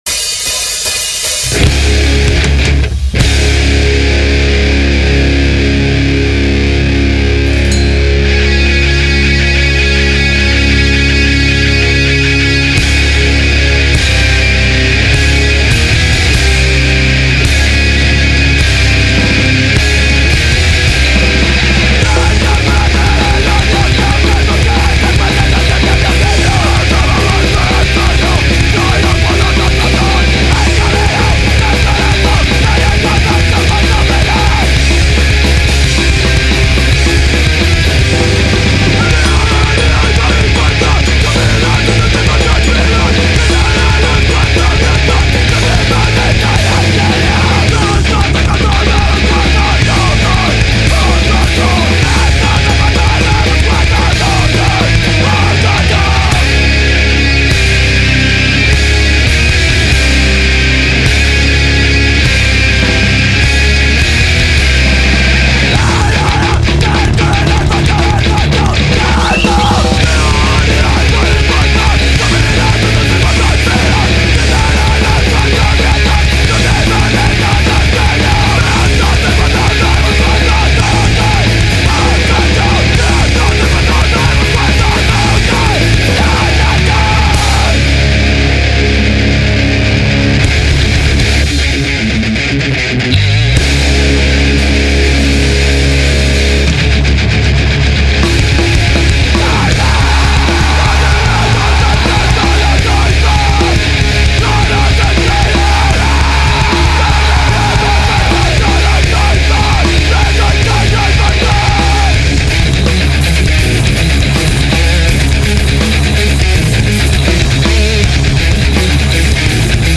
Dark and Apocaliptic Crustcore